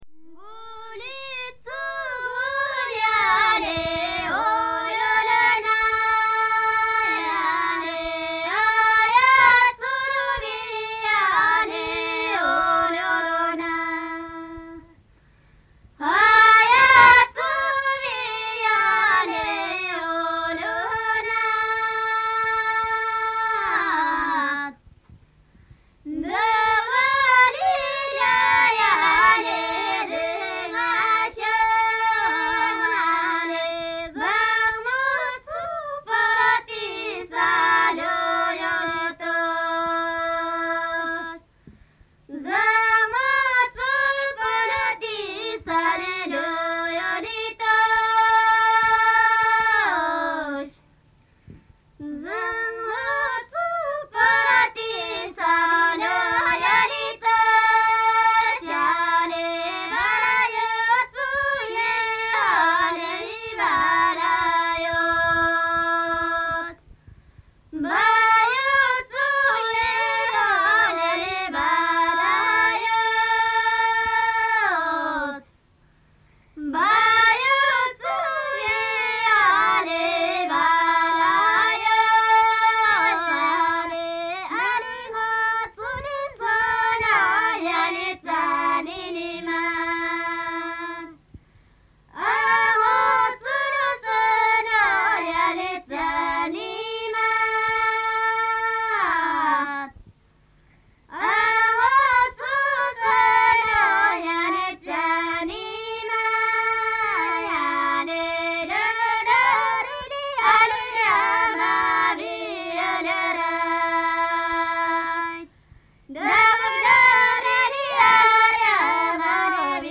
Women singing
From the sound collections of the Pitt Rivers Museum, University of Oxford, being from a collection of reel-to-reel recordings of music and spoken language (principally Thulung Rai)
in Nepal and India between 1970 and 1981.